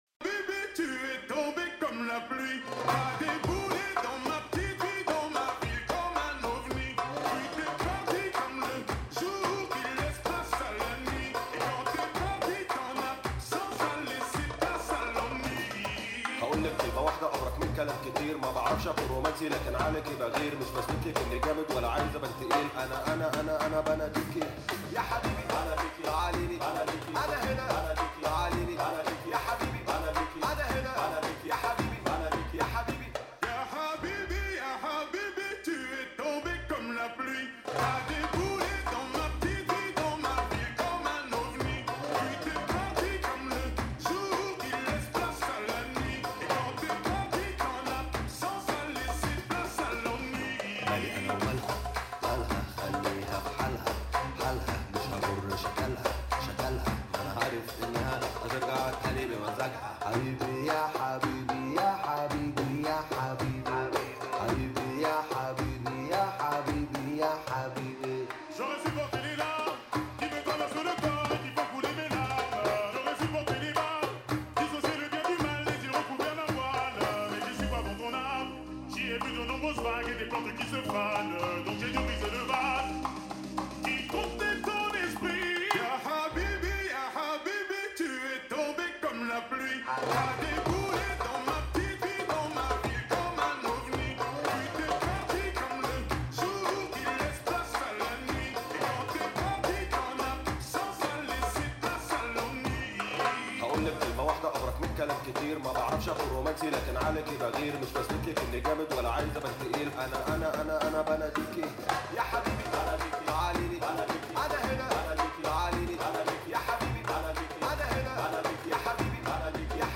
לצערי האיכות לא משהו אבל לא נורא, אפשר לשמוע גם ככה